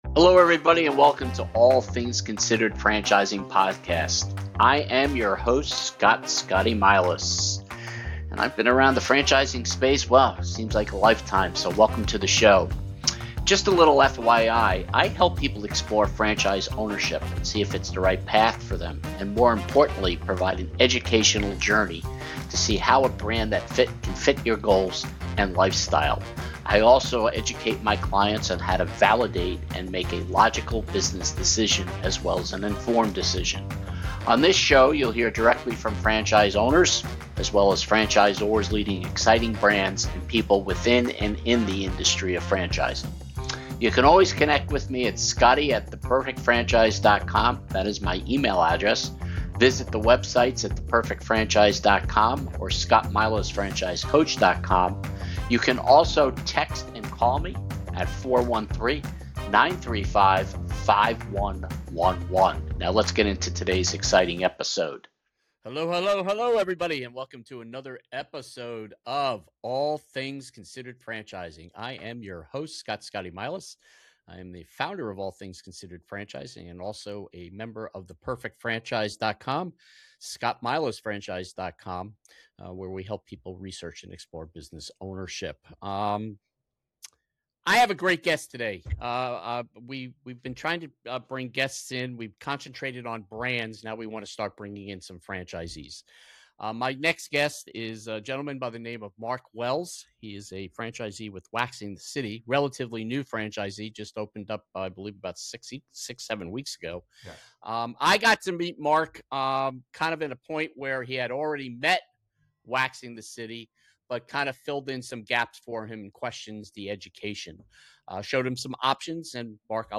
Subscribe for more real conversations about franchise ownership